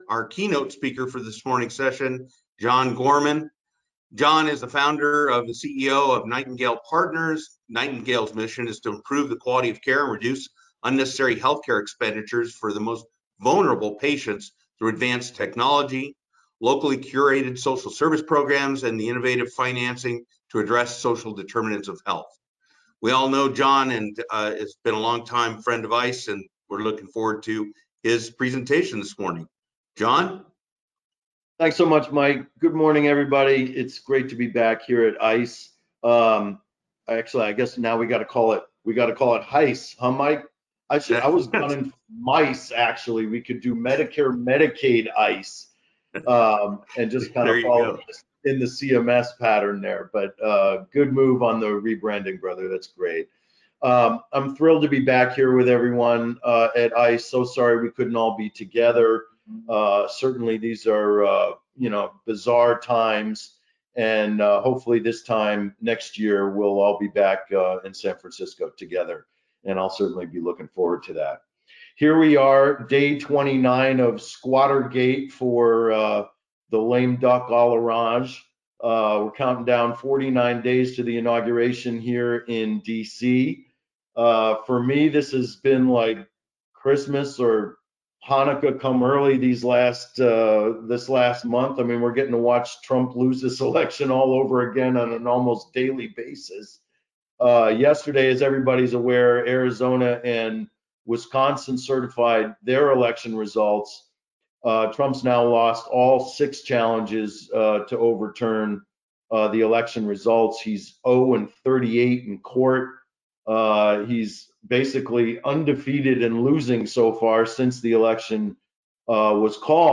Download session audio (MP3 format) and slides (PDF format) from past HICE conferences and training sessions from this page.